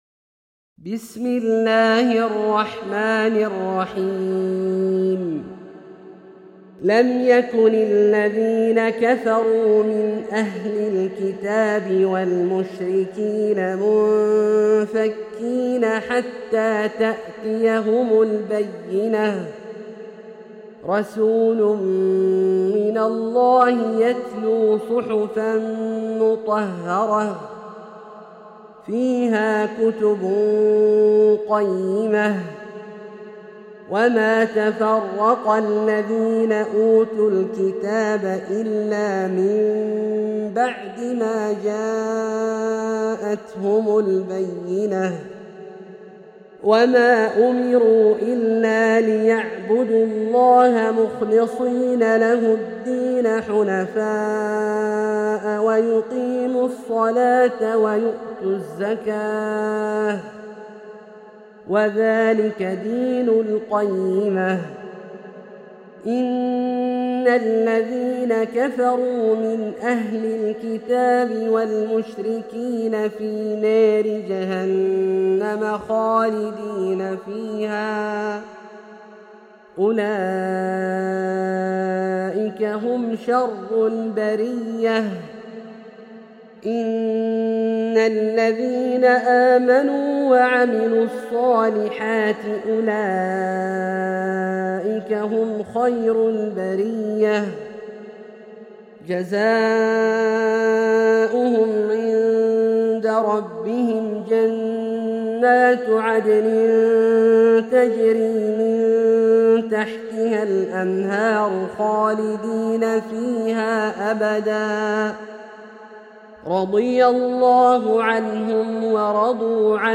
سورة البينة - برواية الدوري عن أبي عمرو البصري > مصحف برواية الدوري عن أبي عمرو البصري > المصحف - تلاوات عبدالله الجهني